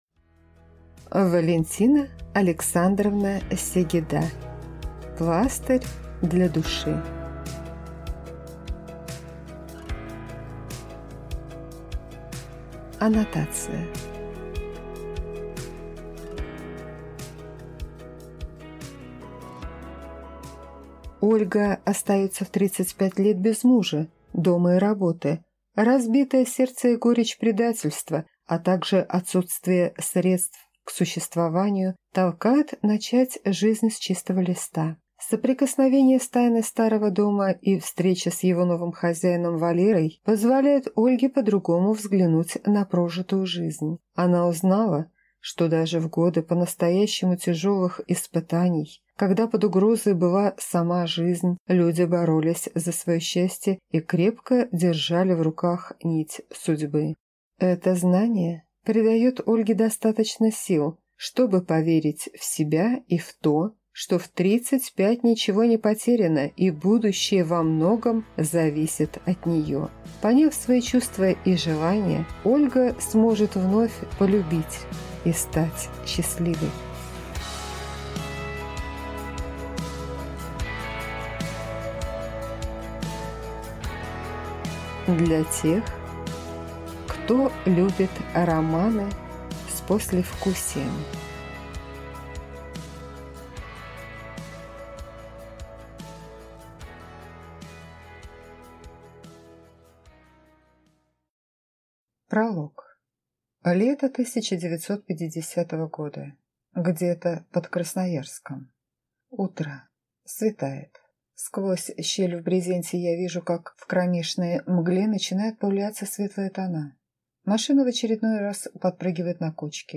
Аудиокнига Пластырь для души | Библиотека аудиокниг